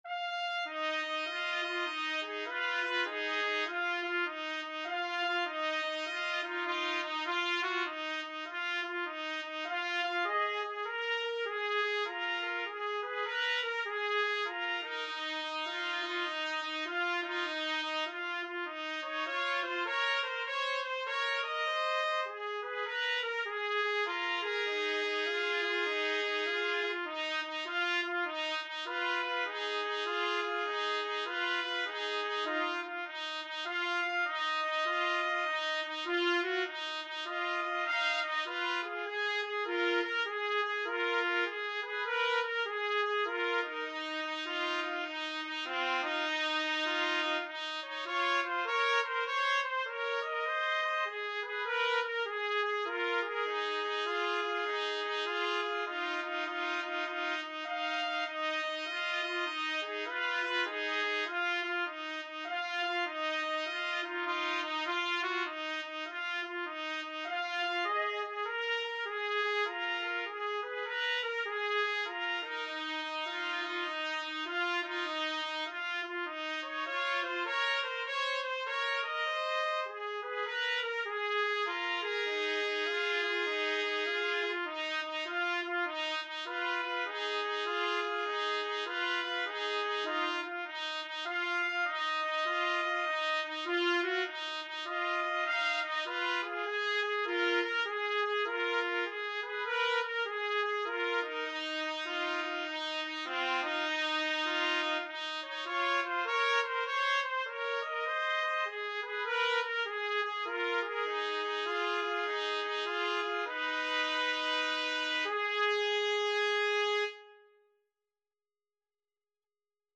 Trumpet Duet version
a popular American 12-bar blues song
Moderato